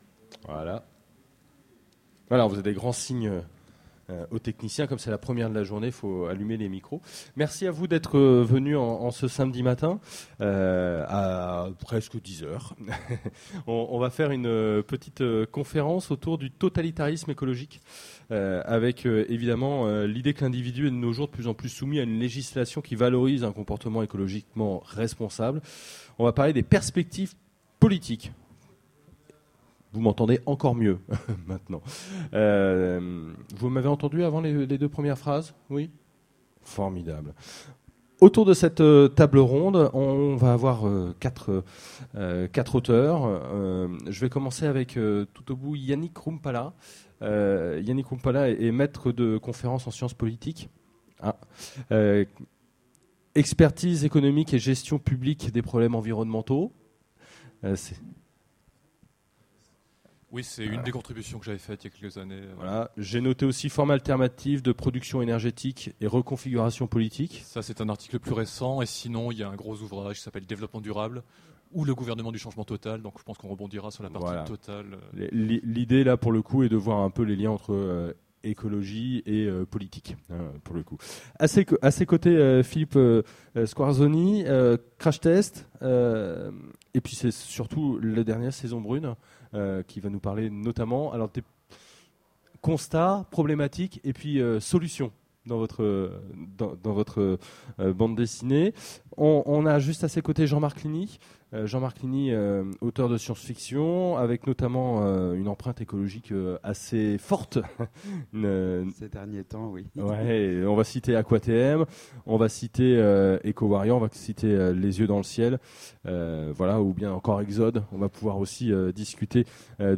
Utopiales 13 : Conférence Le totalitarisme écologique